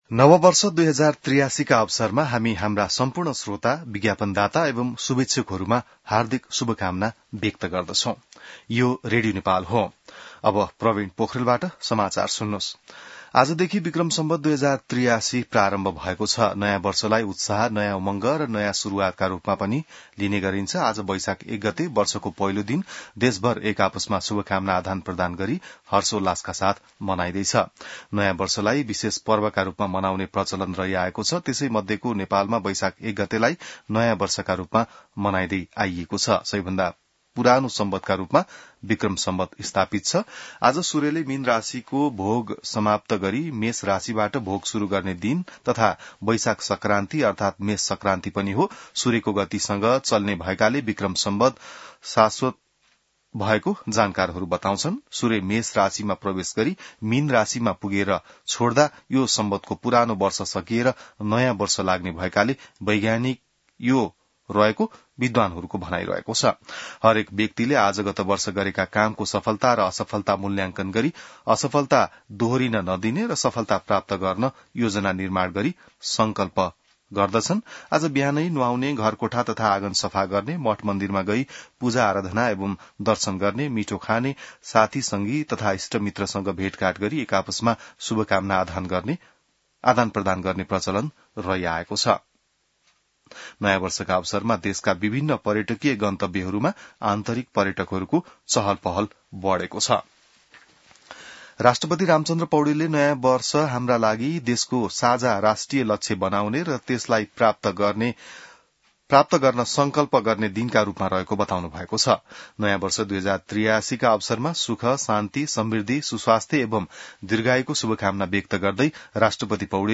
बिहान ६ बजेको नेपाली समाचार : १ वैशाख , २०८३